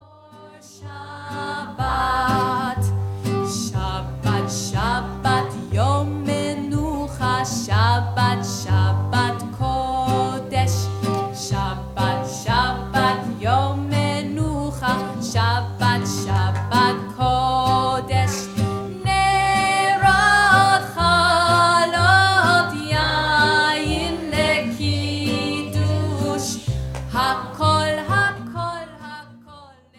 Recorded before an audience of children